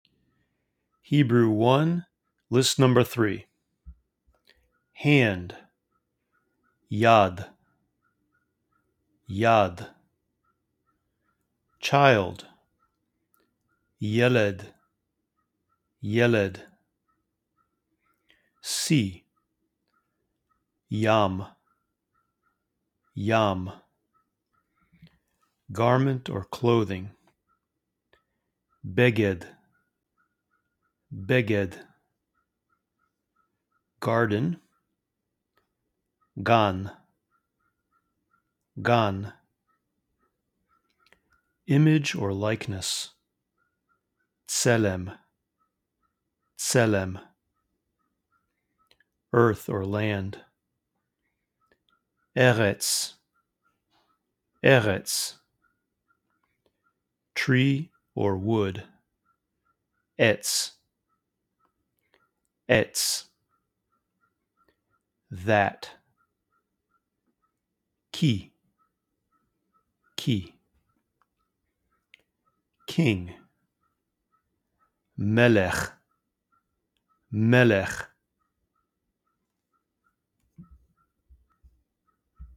List 3 vocabulary words to help you memorize. You’ll hear each word in English, then twice in Hebrew. Use the pauses between words to repeat the Hebrew words.